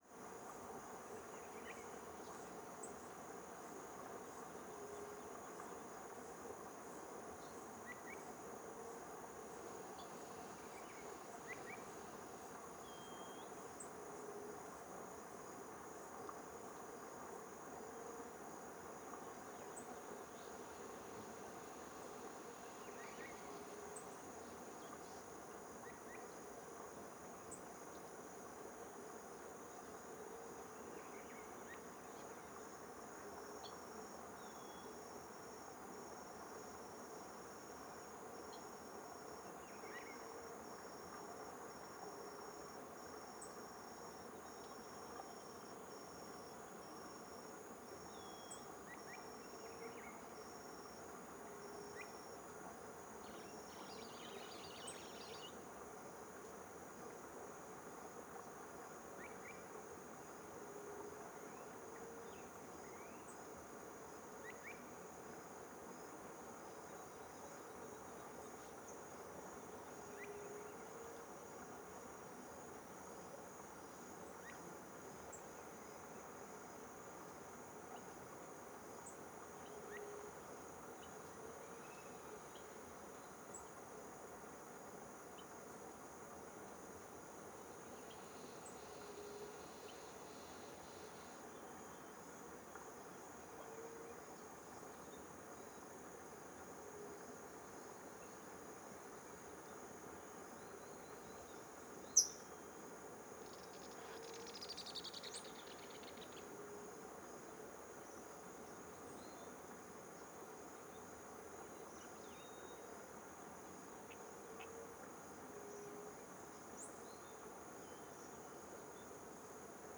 CSC-05-112-OL- Ambiente fim de tarde baixo a ponte agua longe passaros e grllos.wav